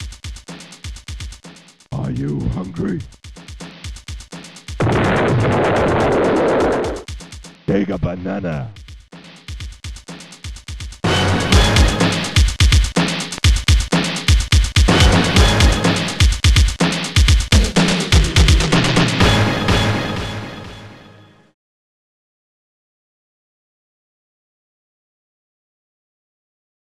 bassdrum
snare
hihat